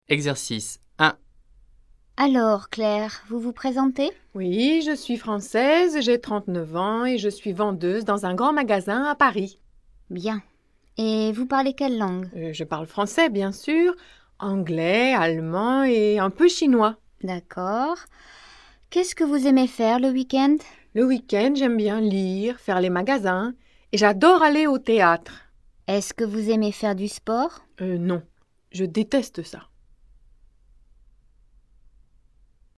Interview
interview.mp3